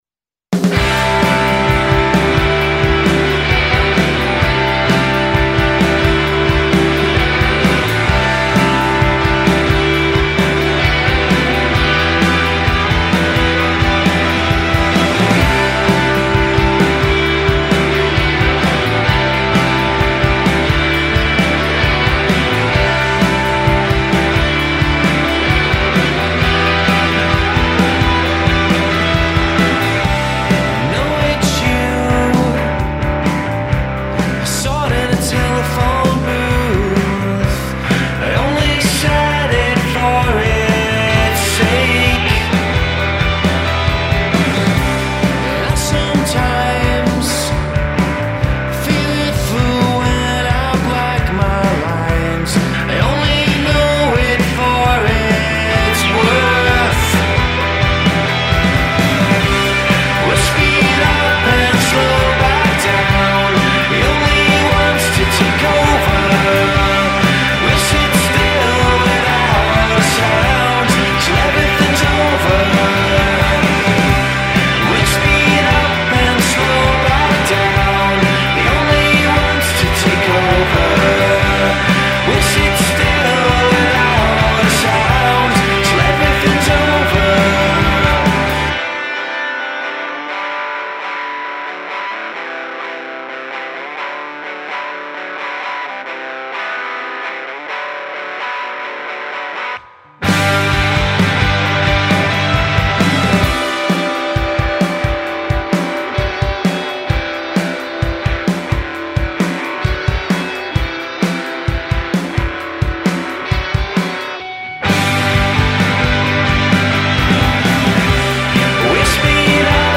lofi indie pop collective